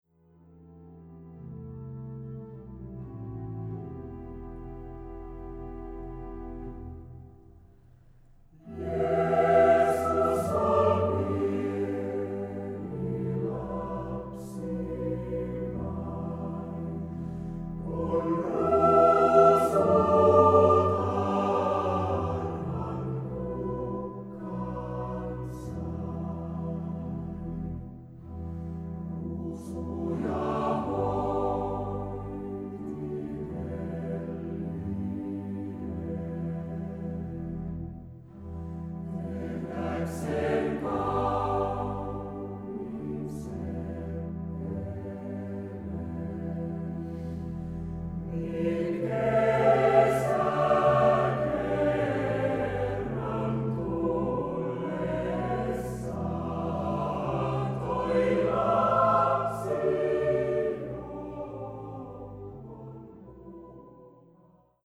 baritoni